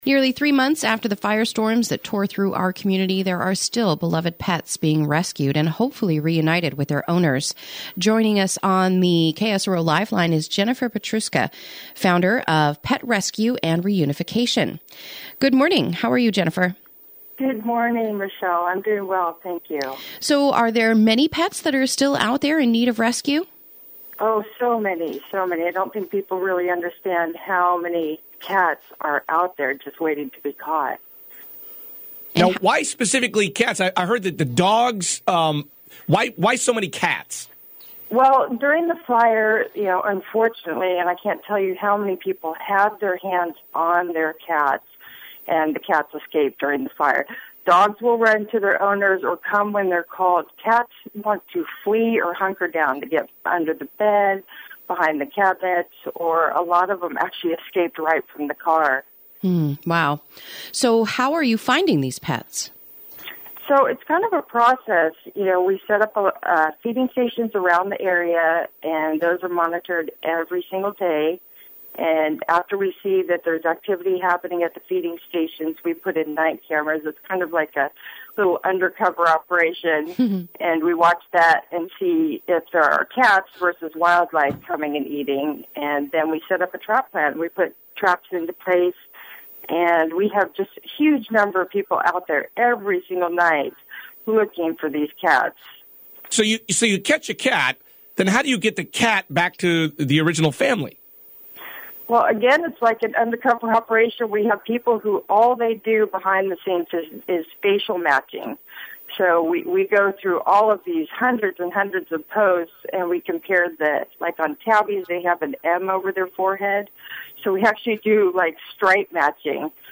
Interview: Pet Rescue and Reunification